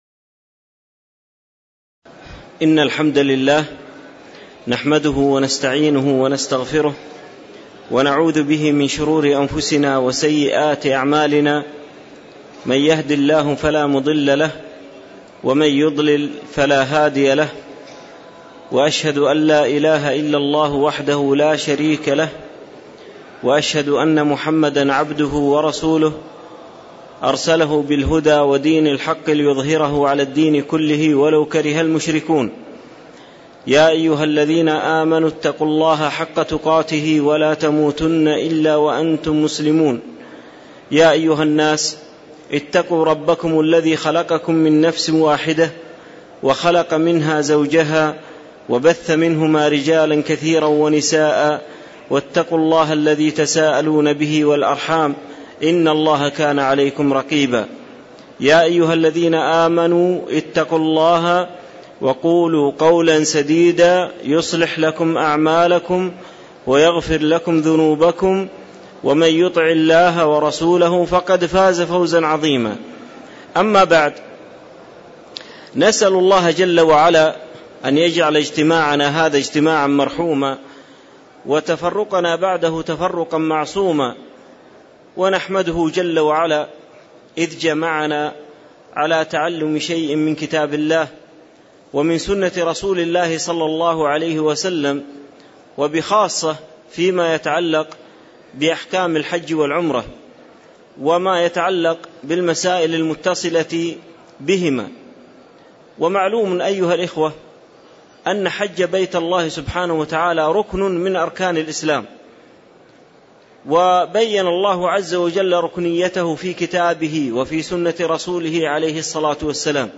تاريخ النشر ٢٥ ذو القعدة ١٤٣٧ هـ المكان: المسجد النبوي الشيخ